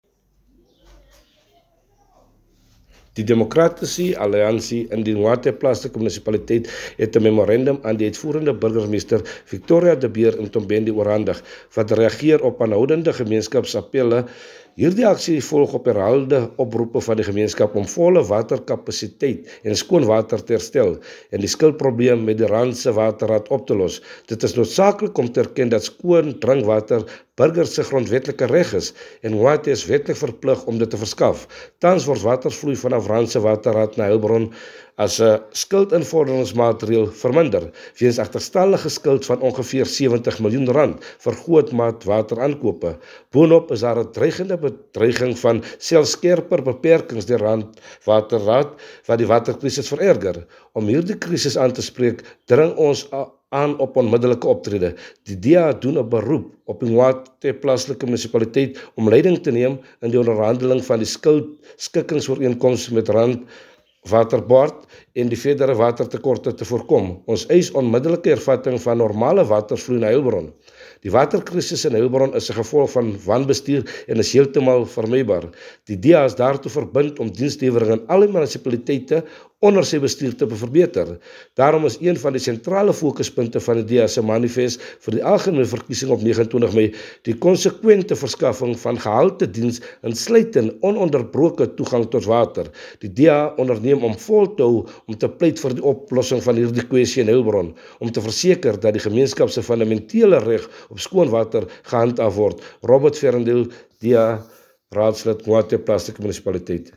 Afrikaans soundbites by Cllr Robert Ferendale.